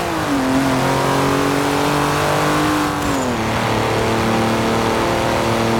fourth_cruise.wav